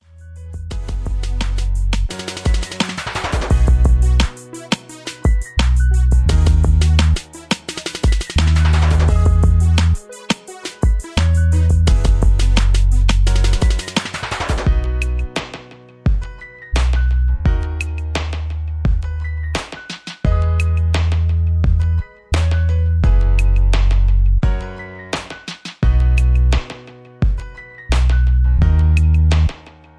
Nice RnB/Pop Beat